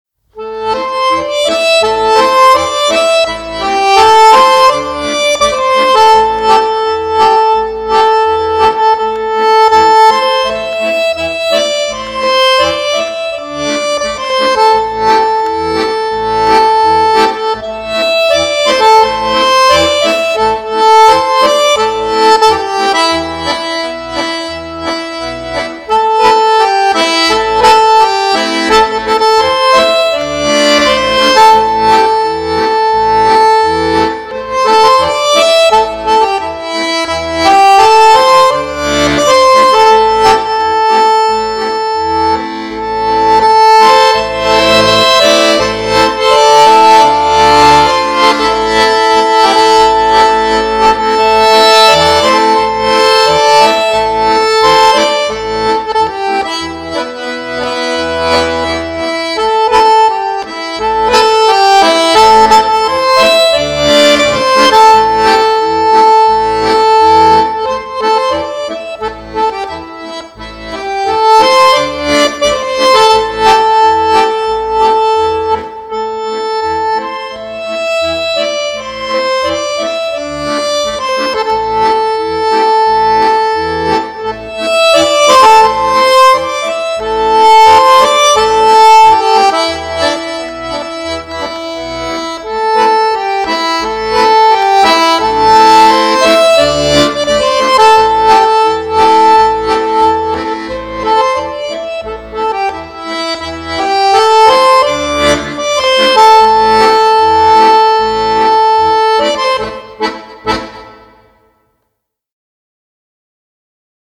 这张唱片不仅能体现出手风琴活泼欢快、连贯轻松的特点，细节更是示范级别；
该赞赏的是线性似的淡定平稳、音效不夸张，特别的HIFI又耐听。